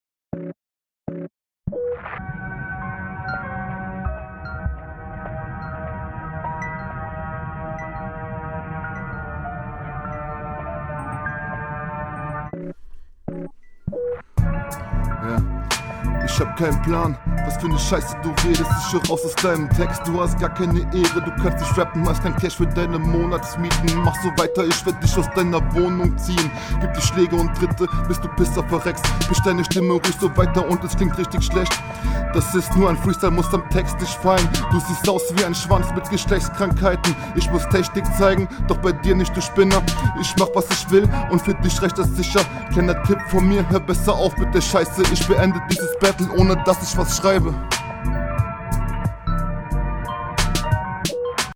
Stimmeinsatz erinnert mich wenig an nem Unroutinierten Gzuz.